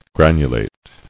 [gran·u·late]